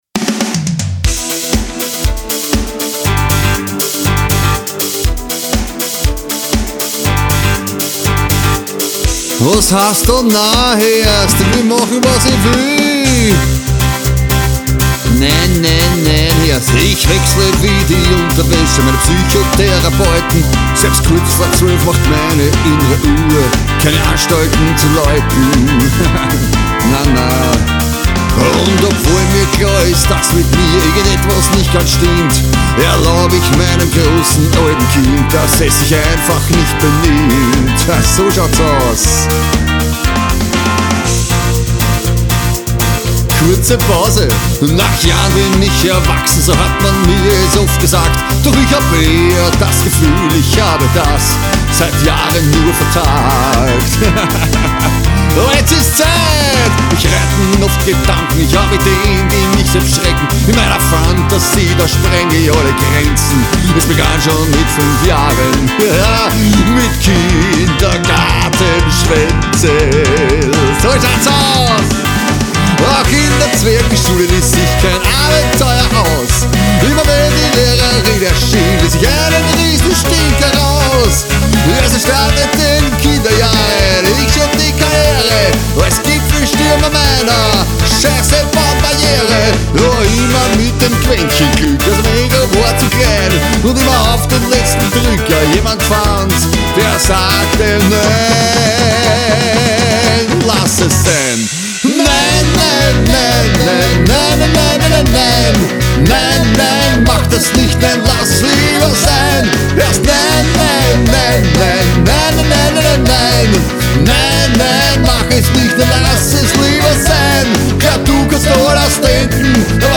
PB und Gesang NEU 2021